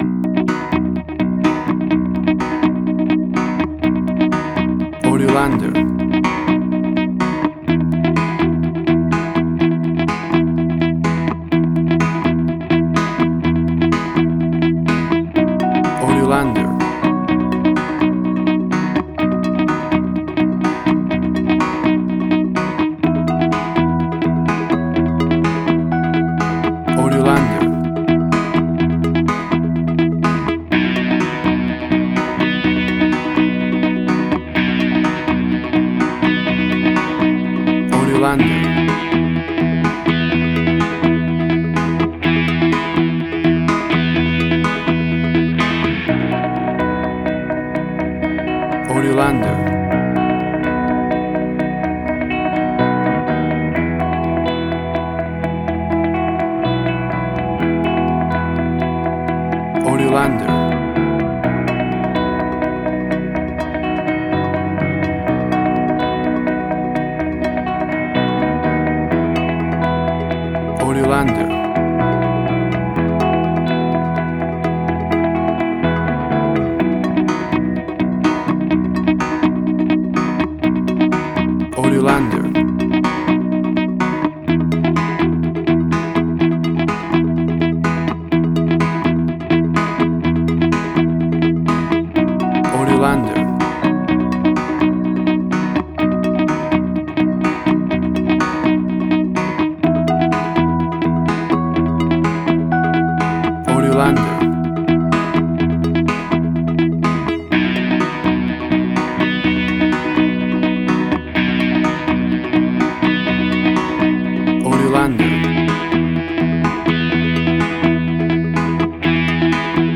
WAV Sample Rate: 16-Bit stereo, 44.1 kHz
Tempo (BPM): 125